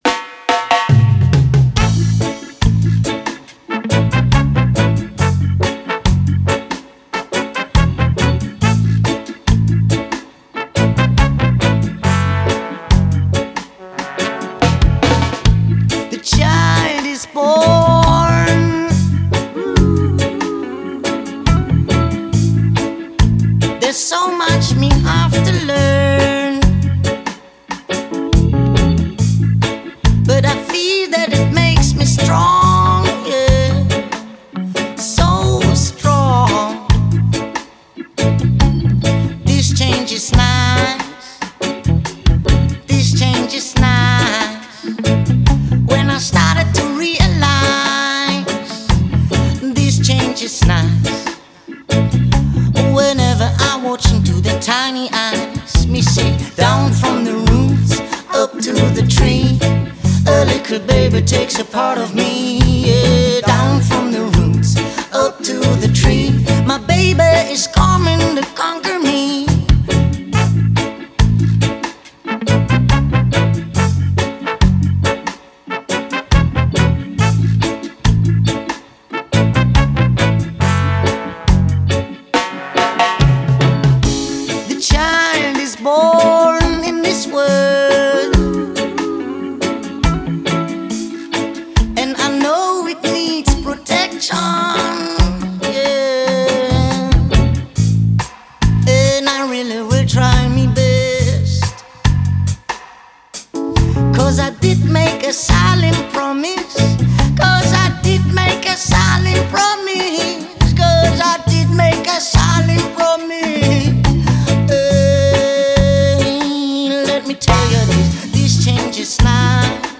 Vocais
Teclados
Baixo
Bateria